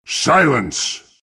silencer-ultimate_26043.mp3